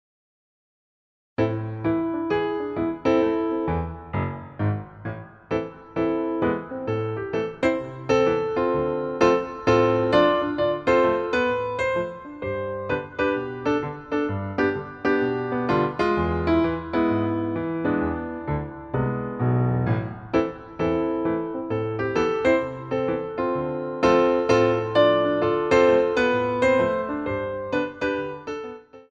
BATTEMENT TENDU III